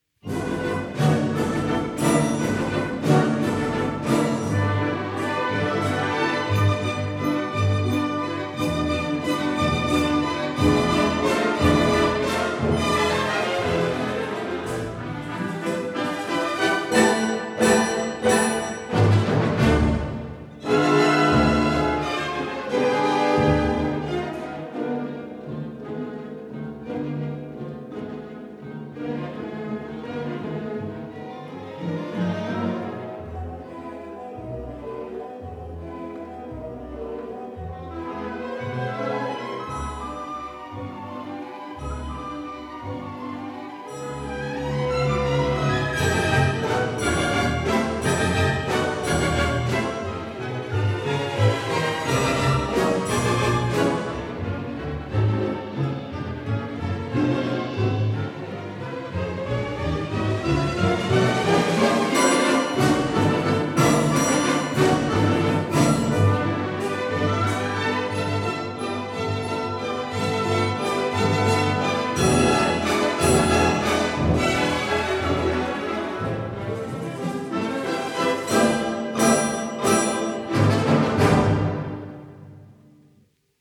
for orchestra
1960 stereo recording